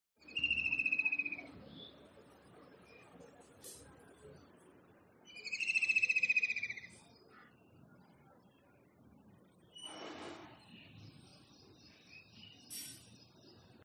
bird.mp3